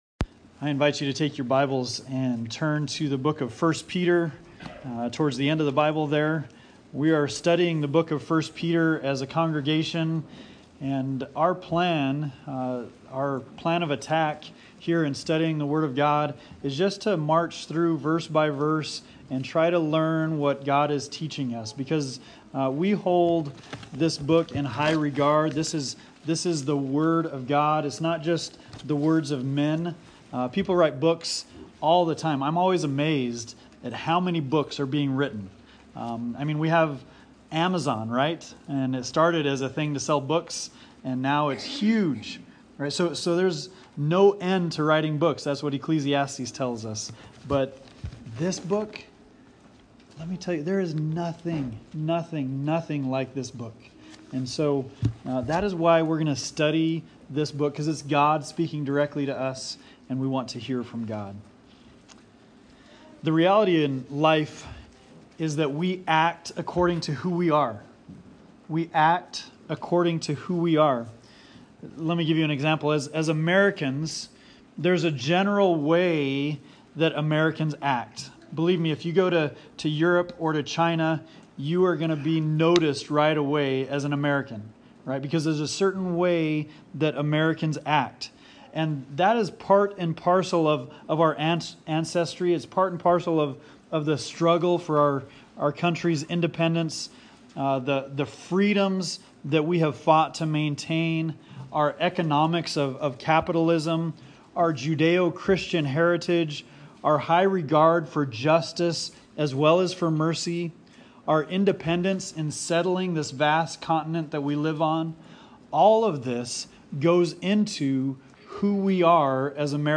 Bible Text: 1 Peter 1:22-25 | Preacher